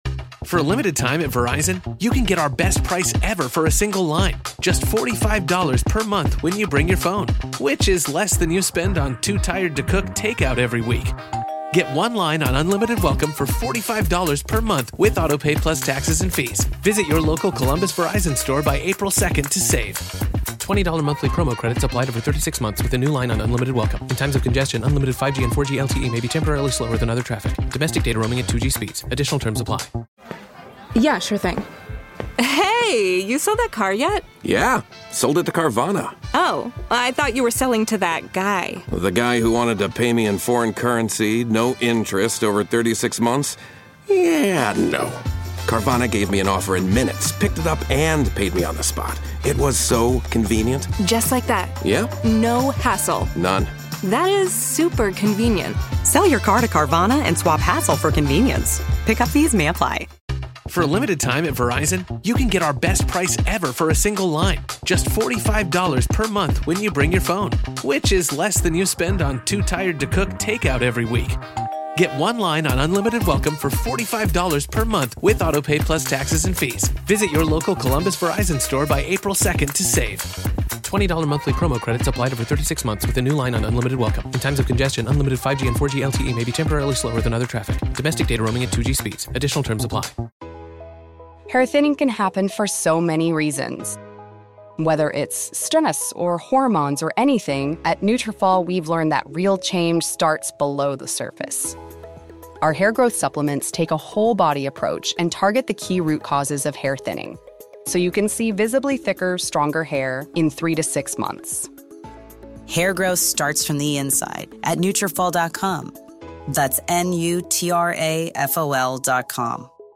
SNL50 Is A Must-Watch For Saturday Night Live Fans + Interview With EP Morgan Neville